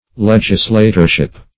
Search Result for " legislatorship" : Wordnet 3.0 NOUN (1) 1. the office of legislator ; The Collaborative International Dictionary of English v.0.48: Legislatorship \Leg"is*la`tor*ship\ (l[e^]j"[i^]s*l[=a]`t[~e]r*sh[i^]p), n. The office of a legislator.
legislatorship.mp3